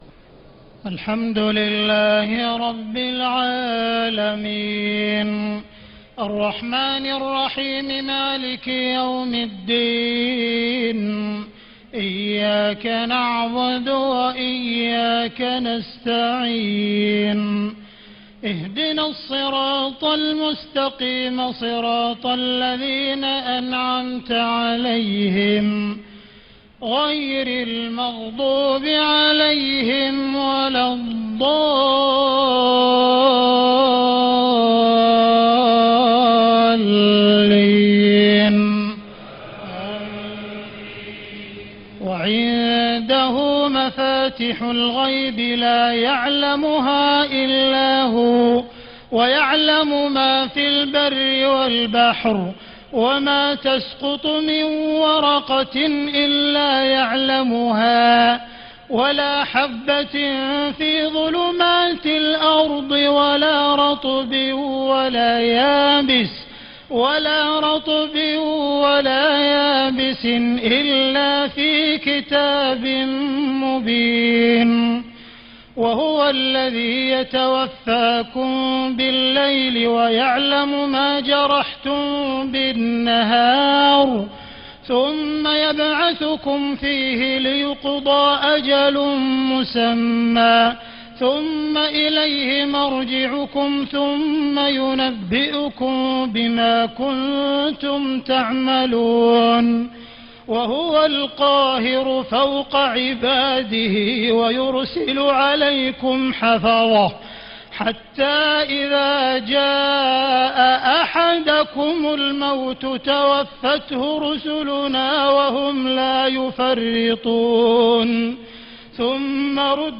تهجد ليلة 27 رمضان 1436هـ من سورة الأنعام (59-111) Tahajjud 27 st night Ramadan 1436H from Surah Al-An’aam > تراويح الحرم المكي عام 1436 🕋 > التراويح - تلاوات الحرمين